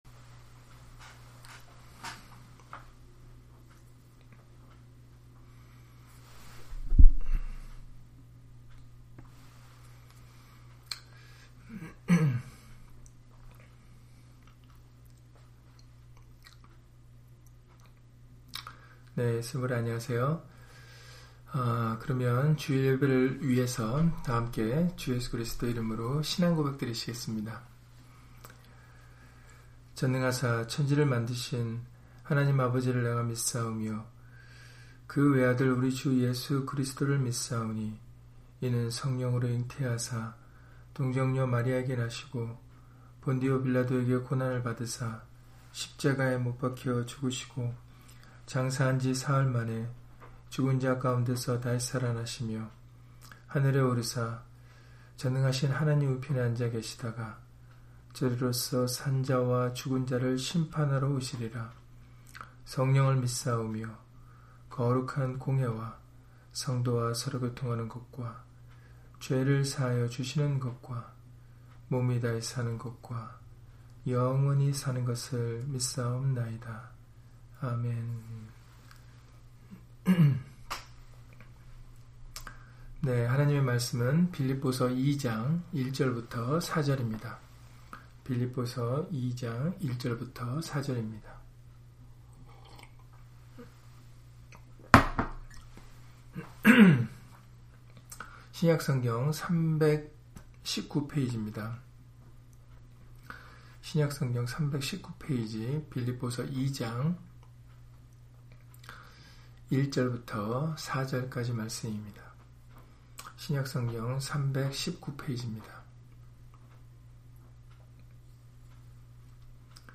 빌립보서 2장 1-4절 [같은 마음, 같은 사랑, 같은 뜻, 같은 말] - 주일/수요예배 설교 - 주 예수 그리스도 이름 예배당